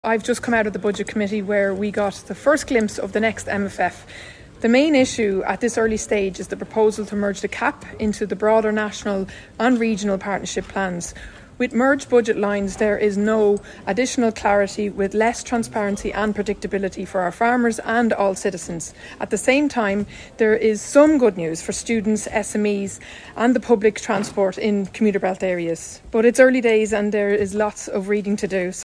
Midlands North West MEP Nina Carberry is concerned at the proposed centralisation of all sectors into one fund………..